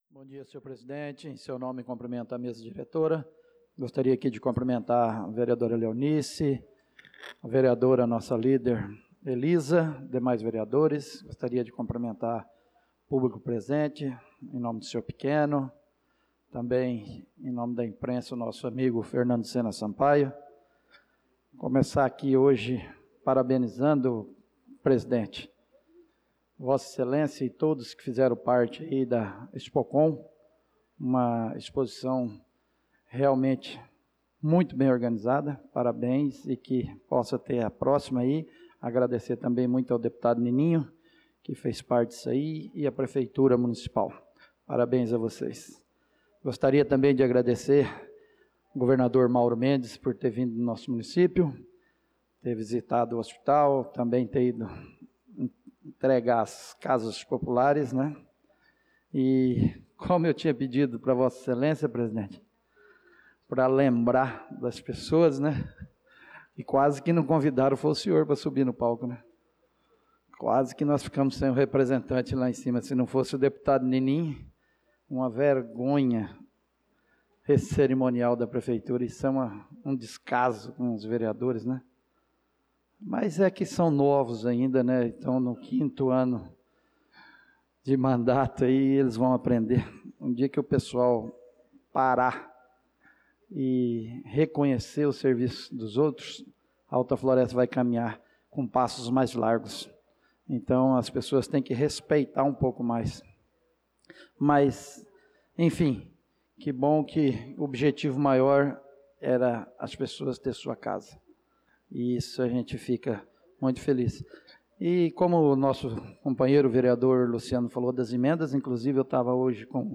Pronunciamento do vereador Marcos Menin na Sessão Ordinária do dia 09/06/2025